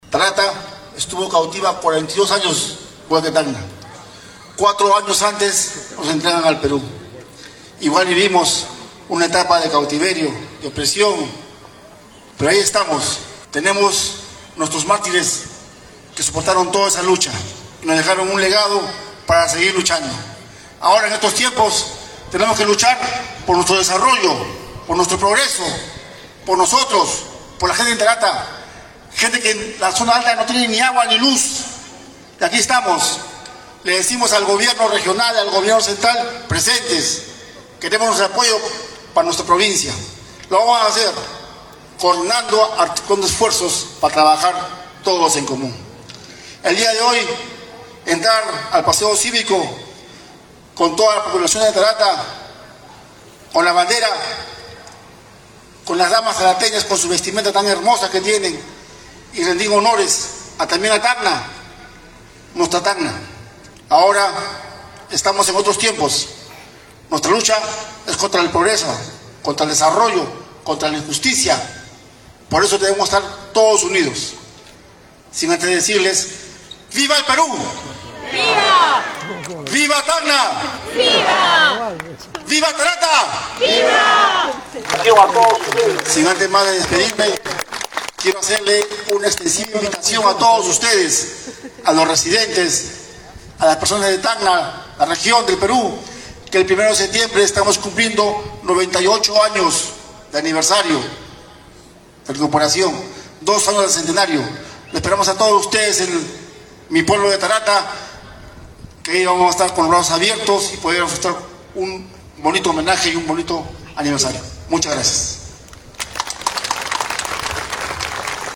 Previo a la llegada de la comitiva a Tacna, el alcalde conversó con Radio Uno expresando que es de suma urgencia conformar el «Comité del Centenario de Tarata».
ALCALDE-TARATA.mp3